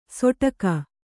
♪ soṭaka